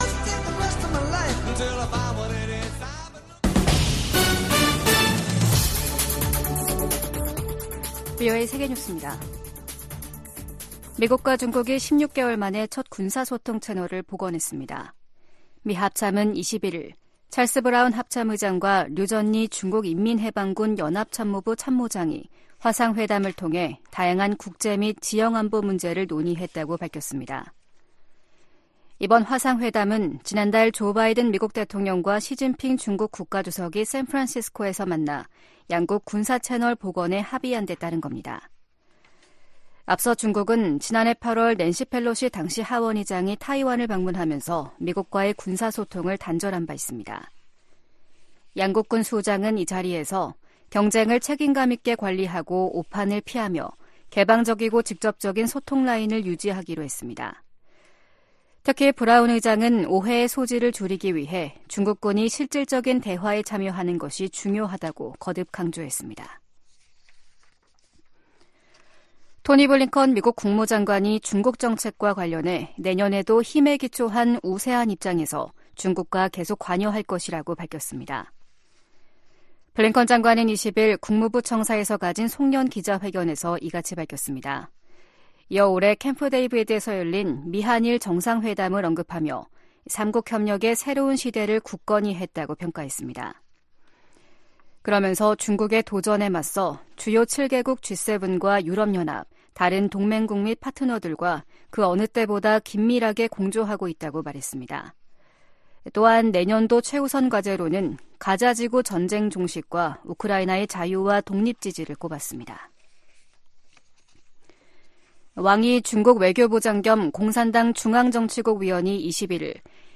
VOA 한국어 아침 뉴스 프로그램 '워싱턴 뉴스 광장' 2023년 12월 22일 방송입니다 유엔 총회가 북한의 조직적이고 광범위한 인권 침해를 규탄하는 결의안을 19년 연속 채택했습니다. 김정은 북한 국무위원장은 어디 있는 적이든 핵 도발에는 핵으로 맞서겠다고 위협했습니다. 토니 블링컨 미 국무장관이 내년에도 중국에 대한 견제와 관여 전략을 병행하겠다는 계획을 밝혔습니다.